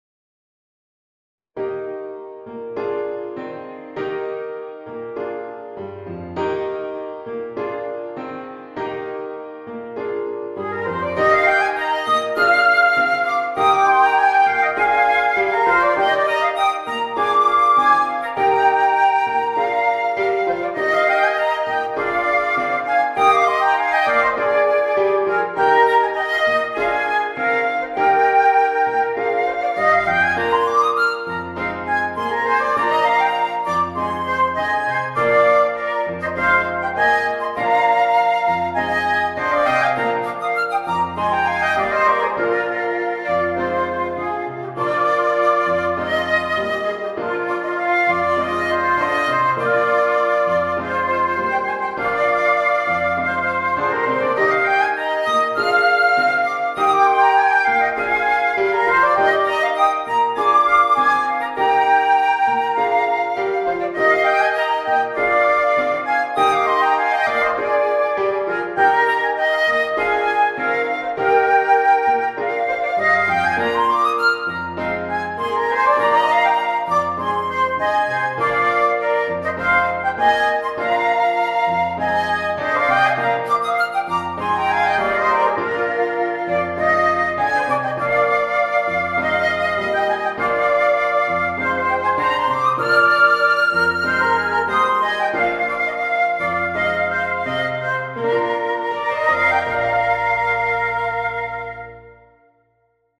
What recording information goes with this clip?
The MP3 was recorded with NotePerformer 3.